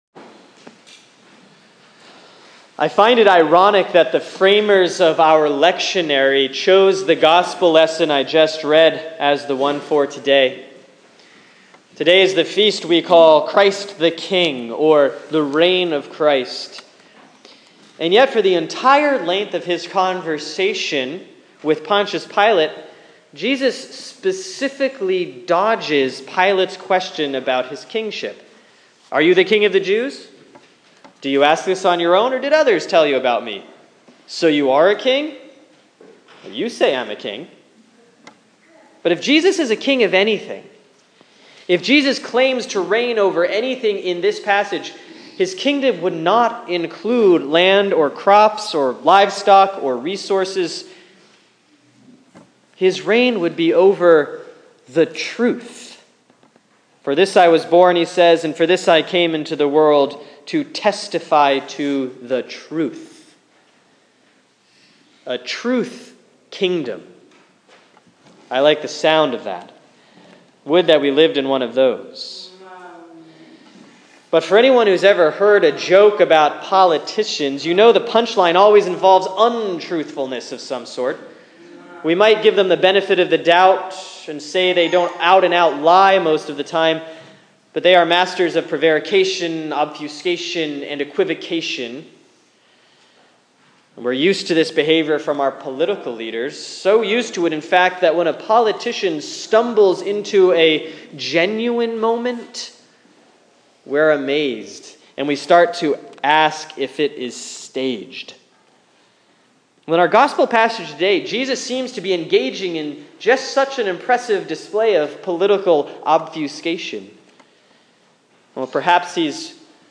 Sermon for Sunday, November 22, 2015 || Christ the King Year B || John 18:33-37